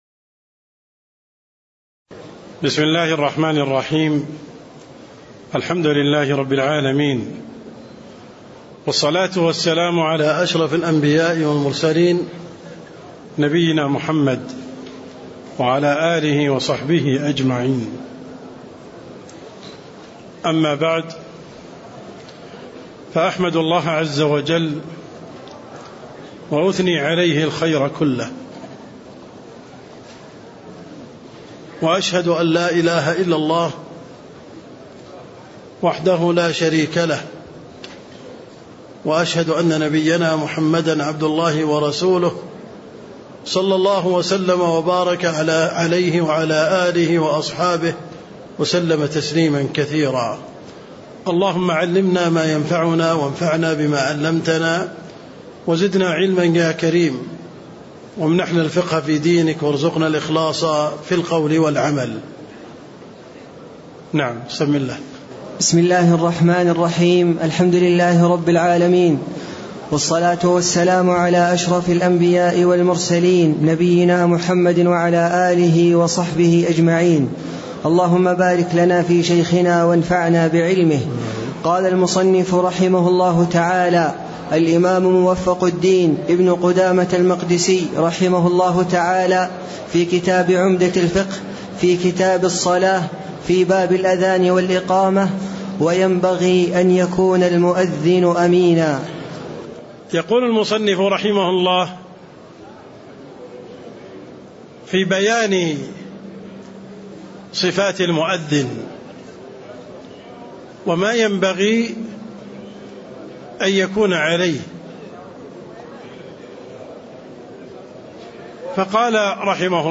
تاريخ النشر ١٤ ذو القعدة ١٤٣٥ هـ المكان: المسجد النبوي الشيخ: عبدالرحمن السند عبدالرحمن السند باب الأذان والإقامة (03)